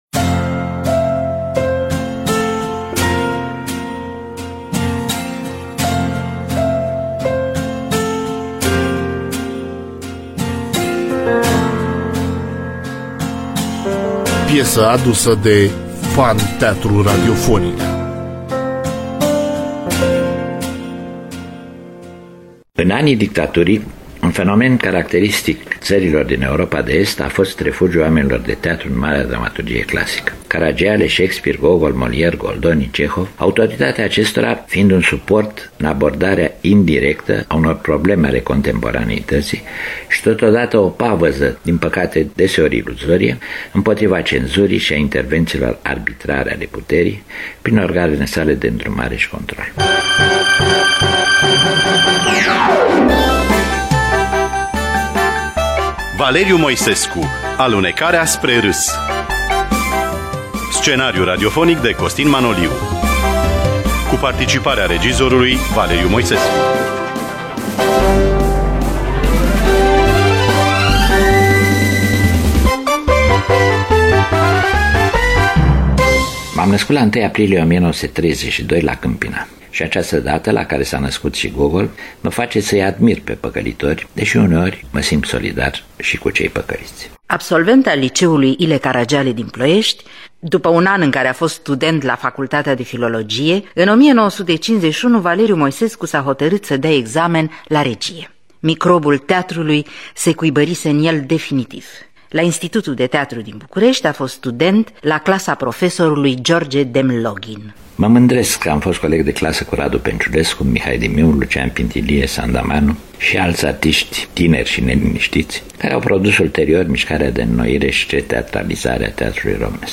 Scenariu radiofonic de Ion-Costin Manoliu. Cu participarea extraordinară a regizorului Valeriu Moisescu.